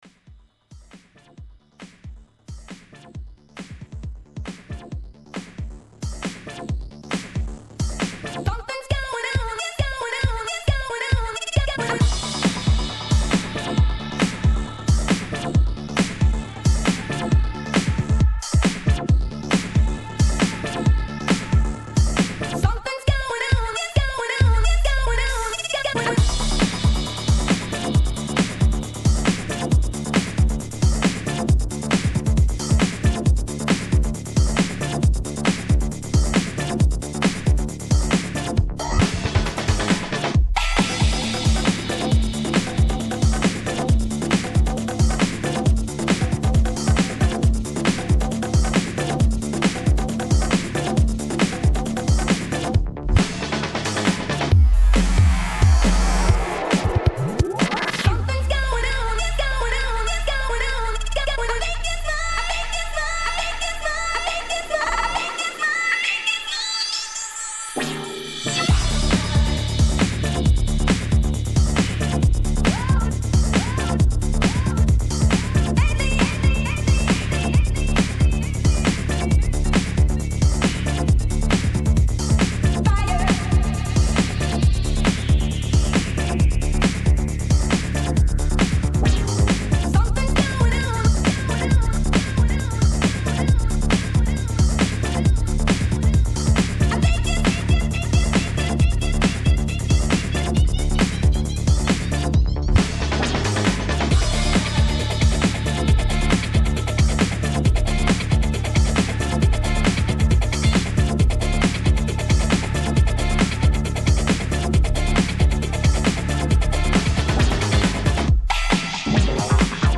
bassline breaks - breaks bootlegs - funky breaks